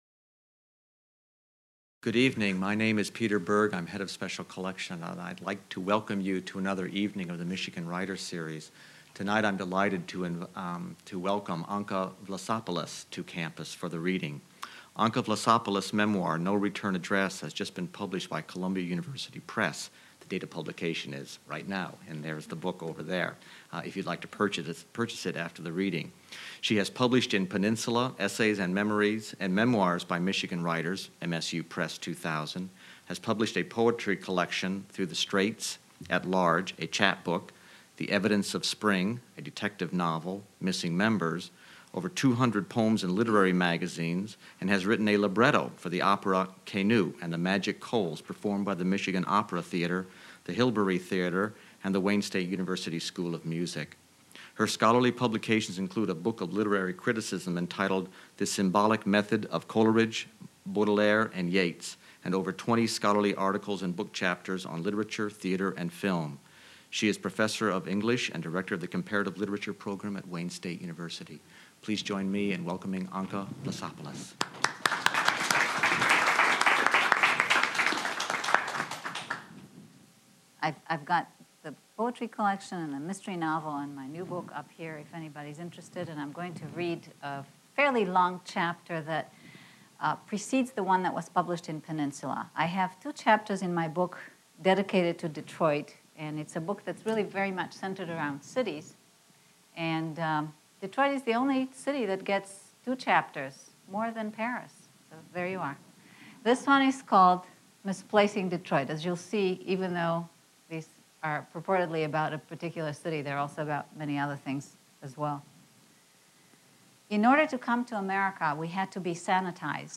Part of the Michigan State University Libraries' Michigan Writers Series.